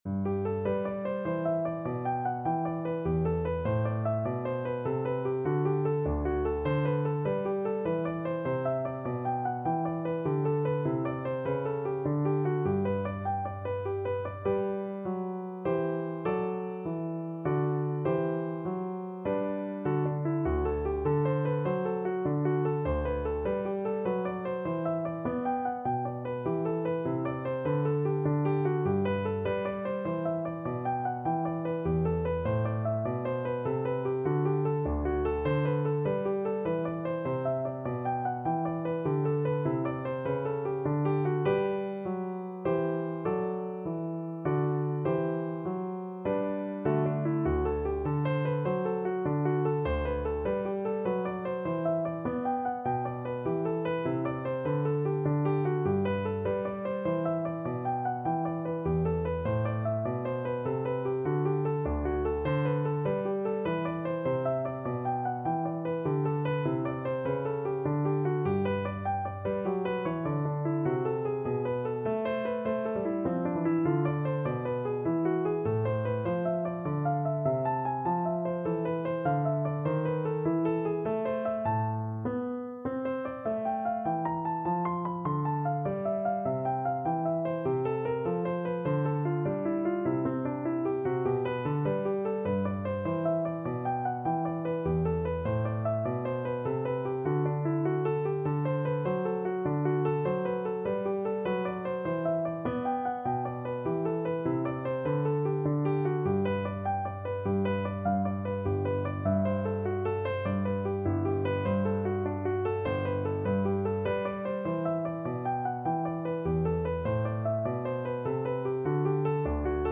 Trombone
3/4 (View more 3/4 Music)
G major (Sounding Pitch) (View more G major Music for Trombone )
Classical (View more Classical Trombone Music)